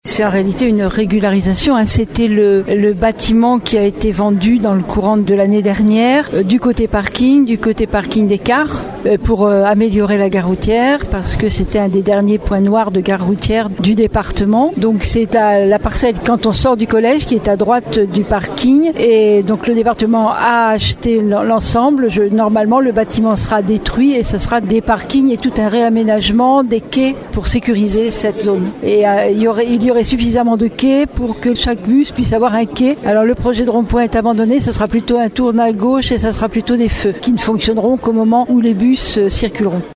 Catherine Desprez, maire de Surgères et vice-présidente du conseil départemental en charge des transports :